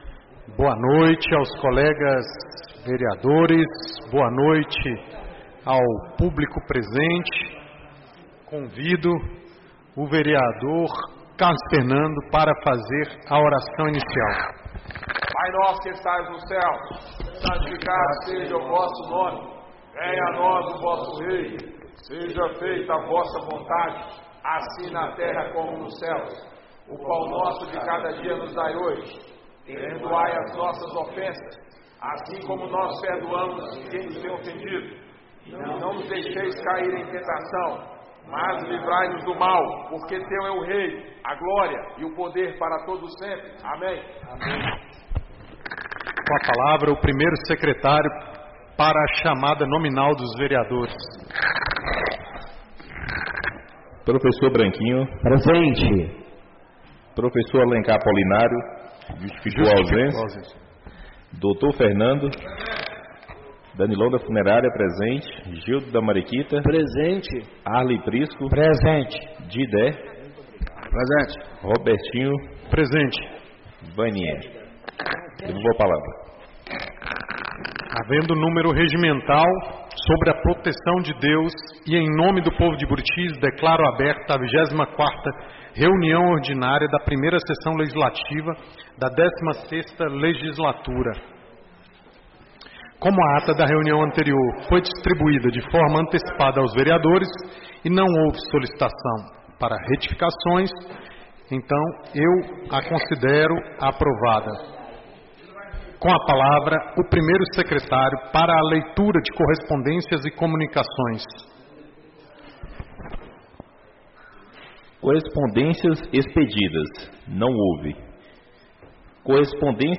24ª Reunião Ordinária da 1ª Sessão Legislativa da 16ª Legislatura - 30-06-25